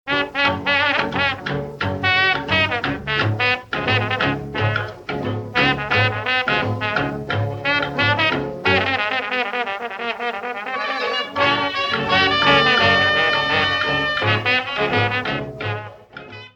Original Restored Recordings Deutsch Français